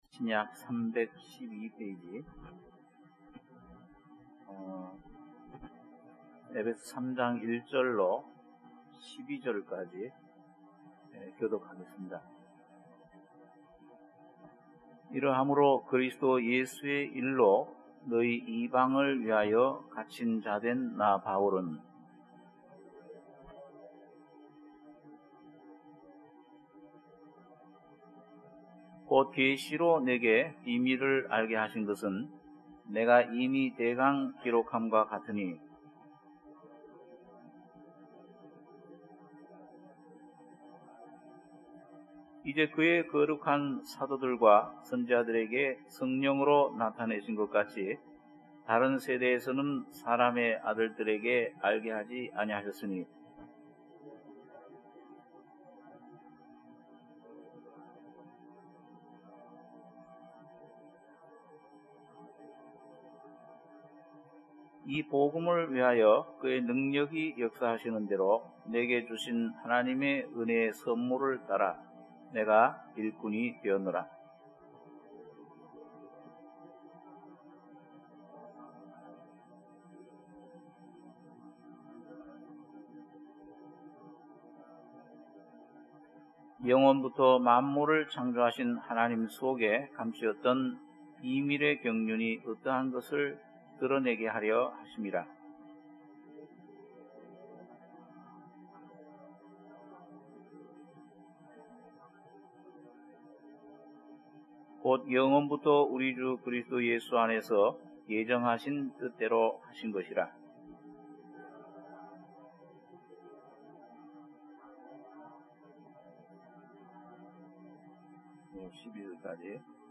주일예배 - 에베소서 3장 1-12절(주일오후)